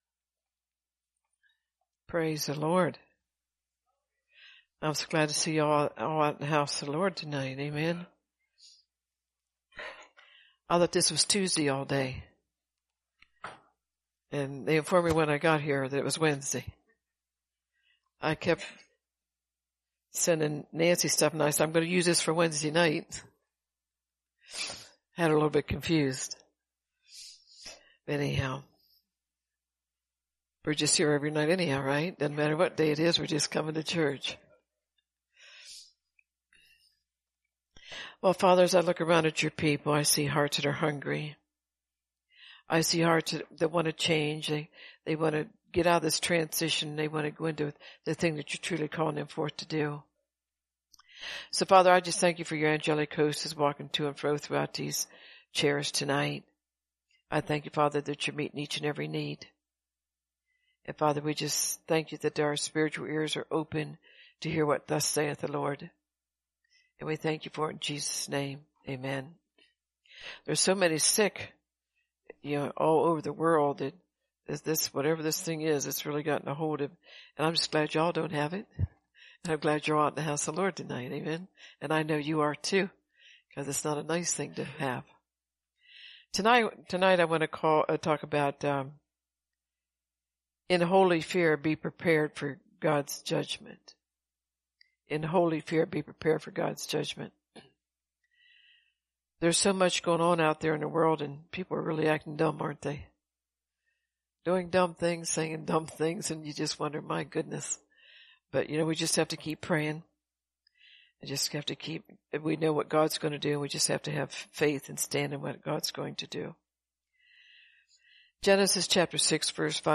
In His Presence Revival